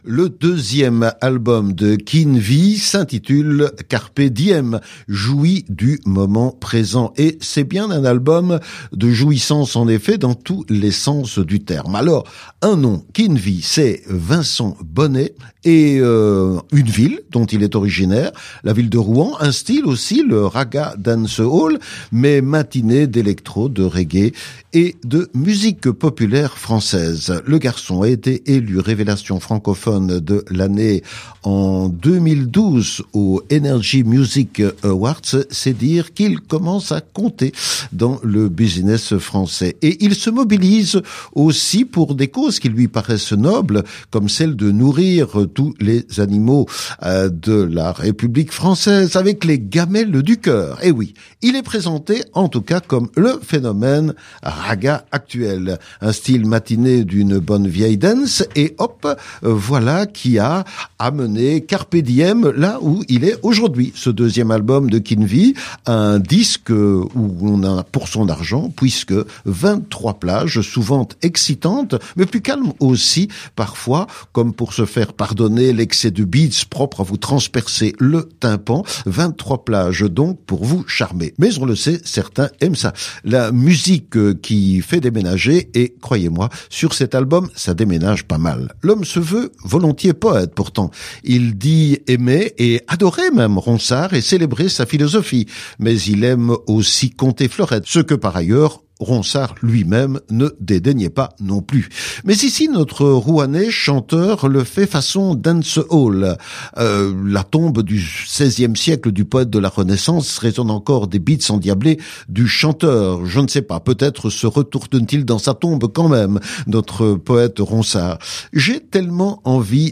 il est chanteur de Ragga Dancehall.